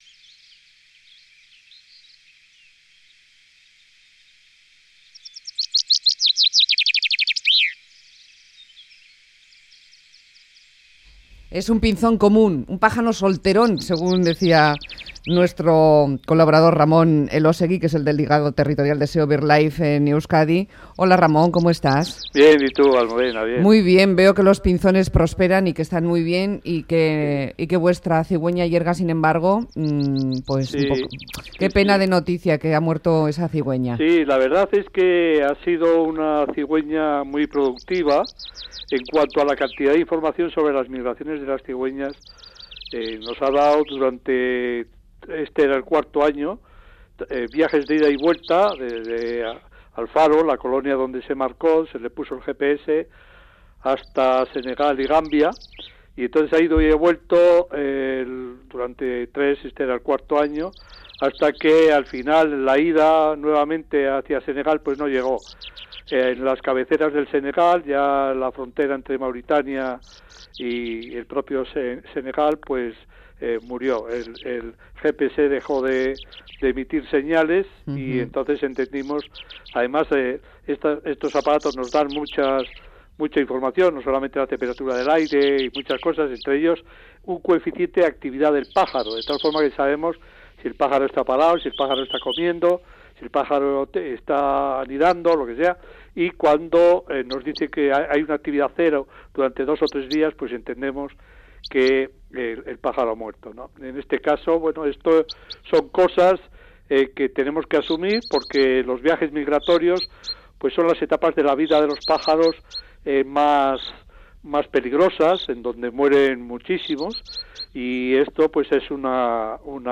nos lleva a escuchar el canto del pinzón vulgar o txoanda.